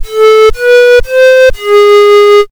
Physical Modelling
01 - Flute Physical Modelling     -
phm-flute.ogg